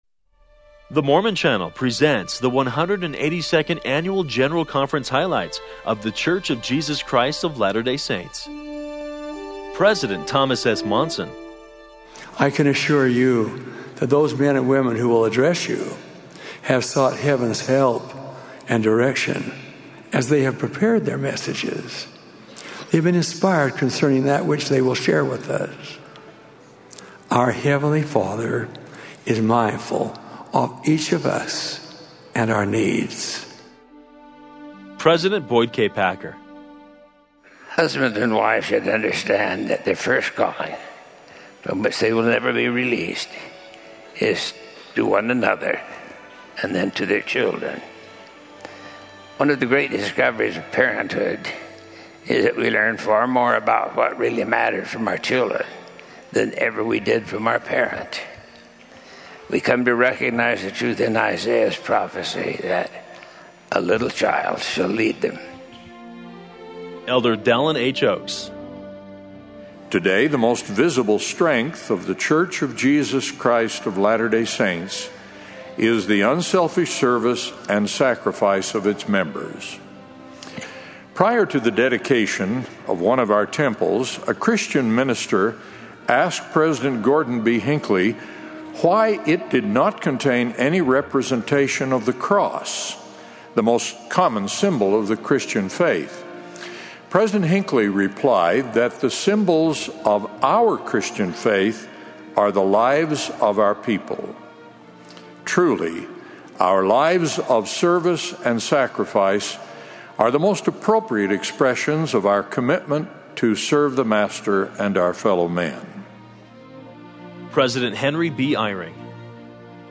For the first time, I recently listened to a 13 minute Highlights audio file which contains sound bites from the talks of the prophet and apostles.
10_General_Conference_Highlights.mp3